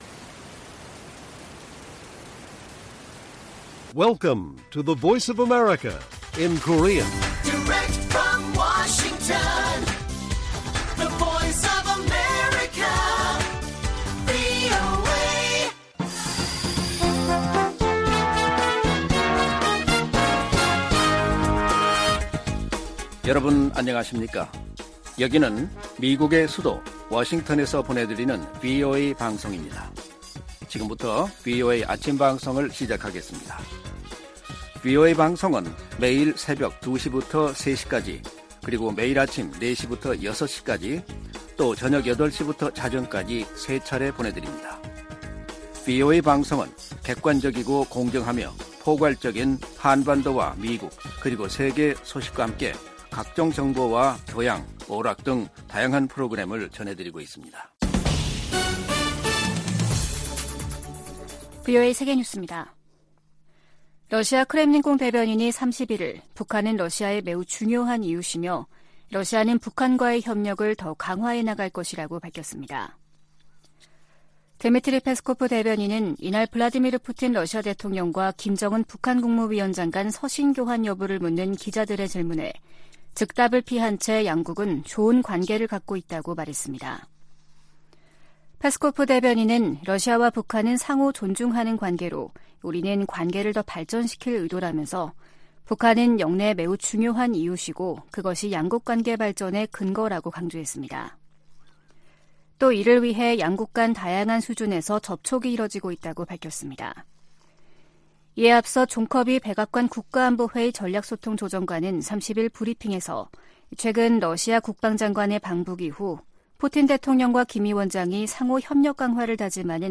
세계 뉴스와 함께 미국의 모든 것을 소개하는 '생방송 여기는 워싱턴입니다', 2023년 9월 1일 아침 방송입니다. '지구촌 오늘'에서는 미국 정부가 사상 처음으로 주권 독립국가를 대상 프로그램을 통해 타이완에 군사장비 지원을 승인한 소식 전해드리고, '아메리카 나우'에서는 허리케인 '이달리아'가 남동부를 강타하면서 피해가 속출한 이야기 살펴보겠습니다.